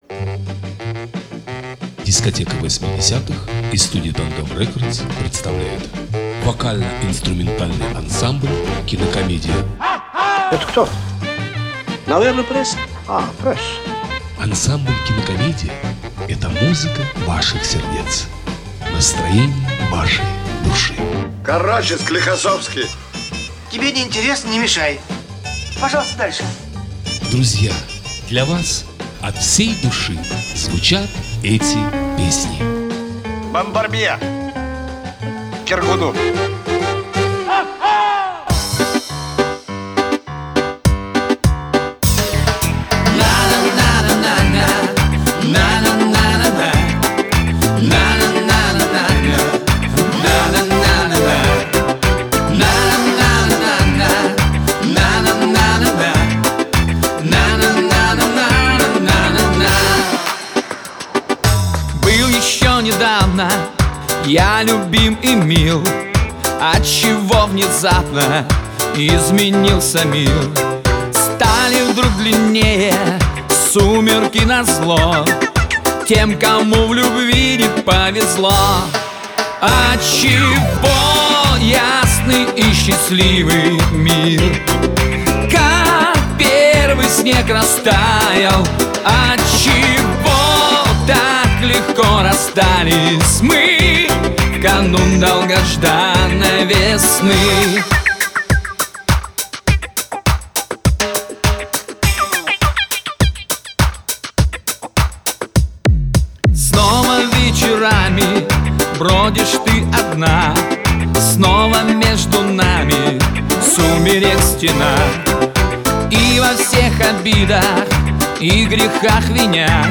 вокал, гитара
вокал, бас-гитара, клавишные
альт-саксофон, клавишные
ударные, перкуссия